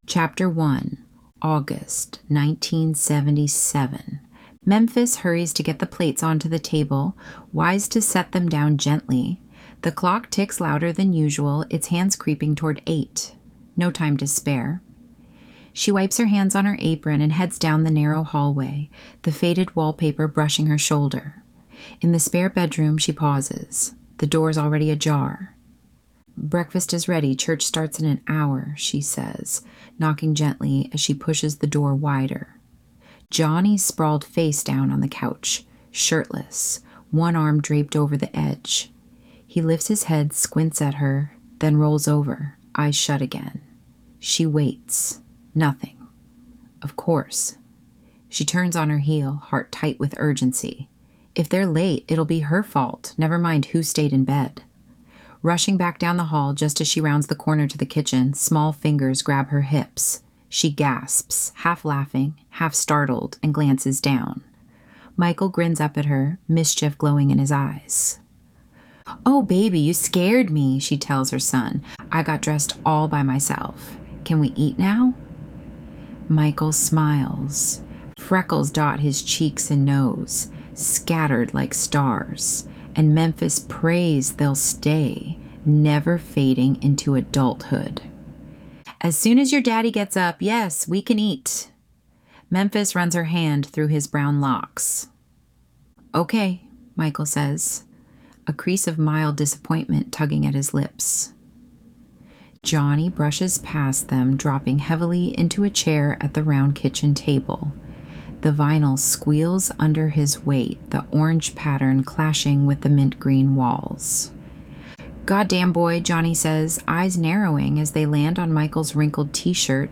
Memphis Audiobook